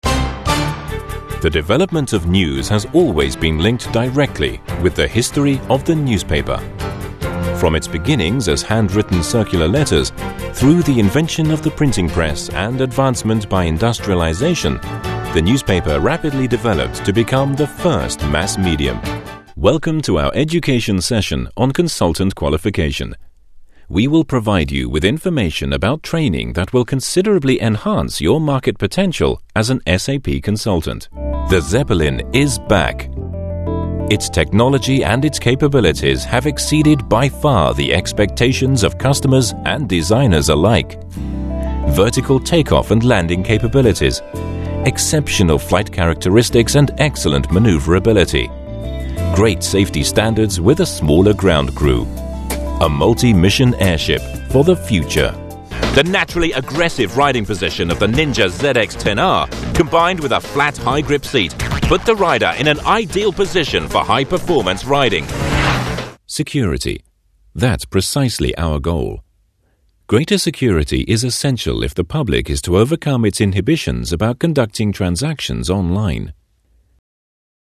British voiceover, Newsreader, TV Commercials, documentaries, professional, Japan specialist, corporate, deep voice, RP, mid-atlantic
britisch
Sprechprobe: Industrie (Muttersprache):
Professional British voiceover.